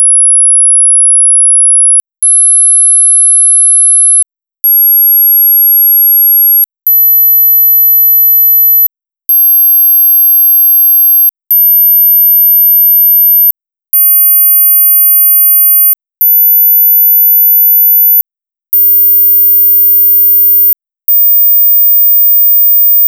Listen to 10,500-15,000hz tones in increments of 500hz and pauses for 1,000hz intervals: